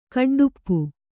♪ kaḍḍuppu